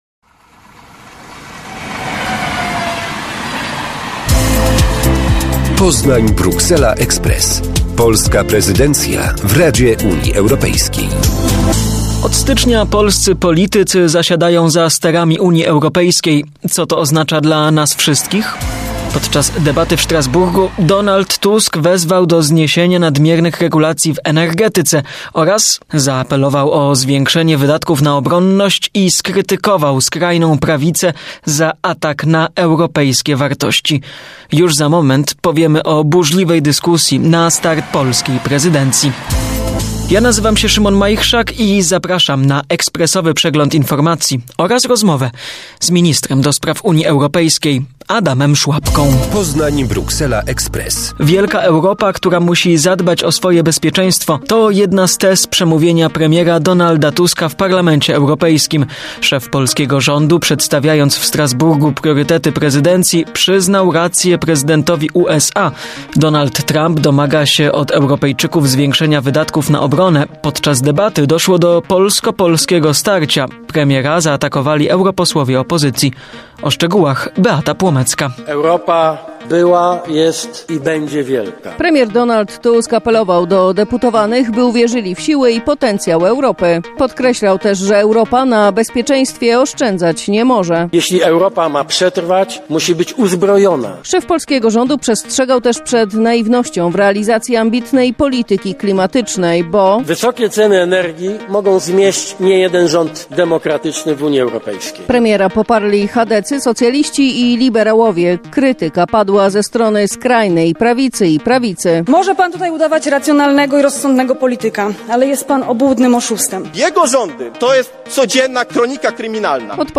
Zapraszam na ekspresowy przegląd informacji oraz rozmowę z Ministrem do spraw Unii Europejskiej, Adamem Szłapką.